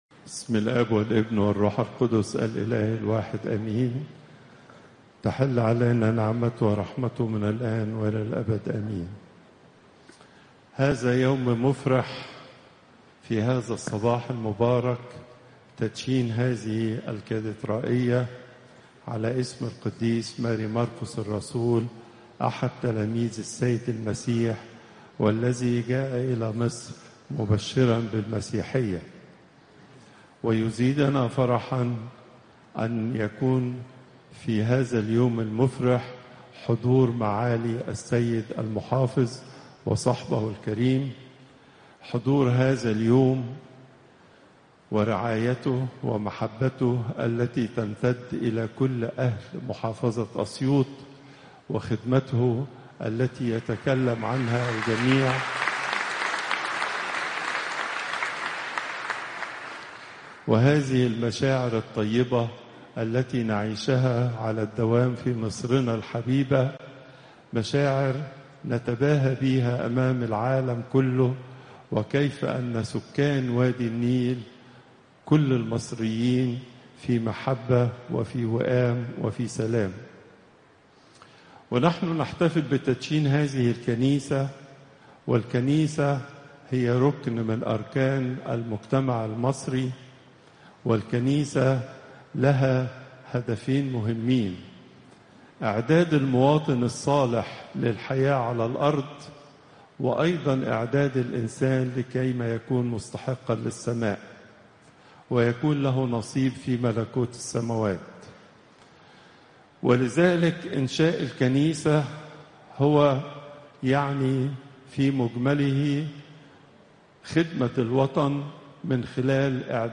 Popup Player Download Audio Pope Twadros II Wednesday, 01 October 2025 11:15 Pope Tawdroes II Weekly Lecture Hits: 49